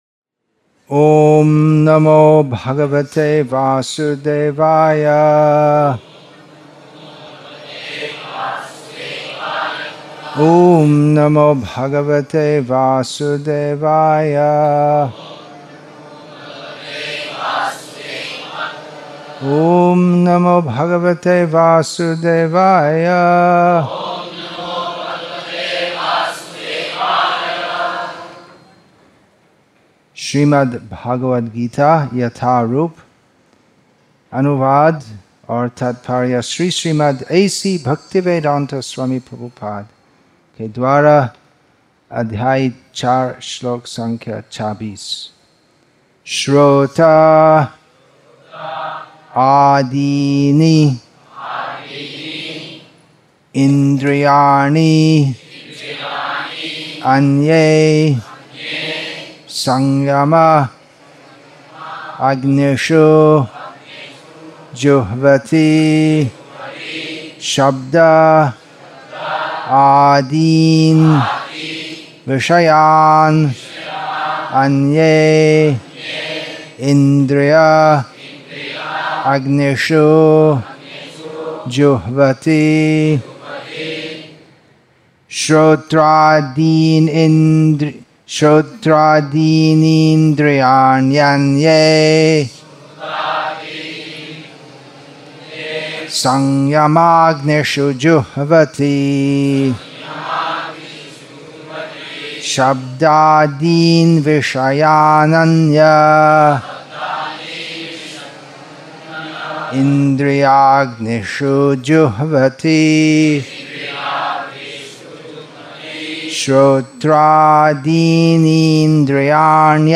English with தமிழ் (Tamil) Translation; Vellore, Tamil Nadu , India